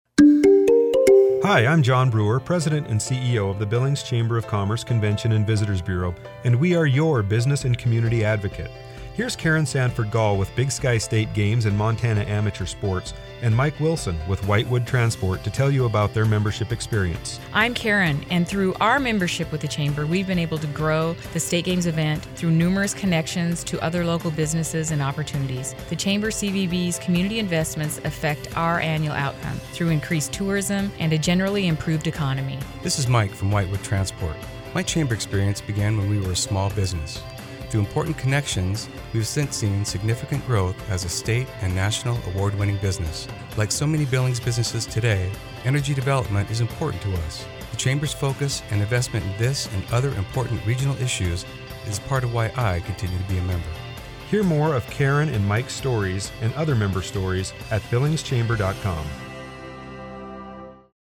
Billings Chamber :60 Spot
Chamber-60-Full-Mix.mp3